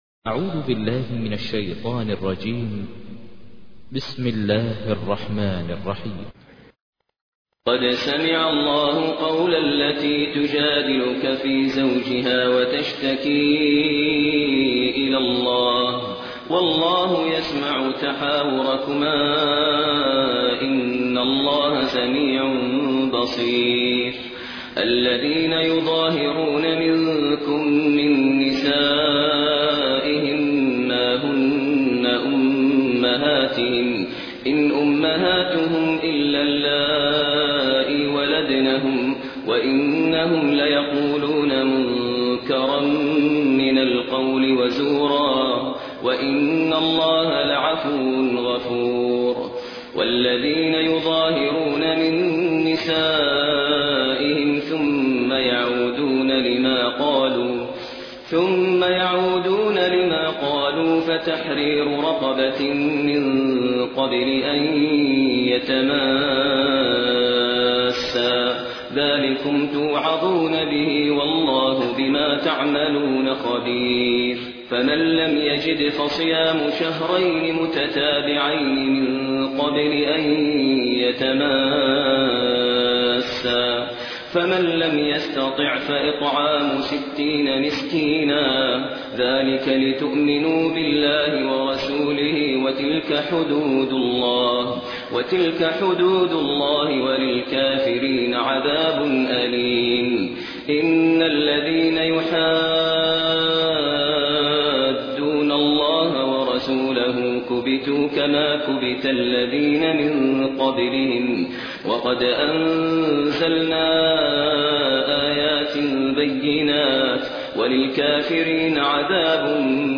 تحميل : 58. سورة المجادلة / القارئ ماهر المعيقلي / القرآن الكريم / موقع يا حسين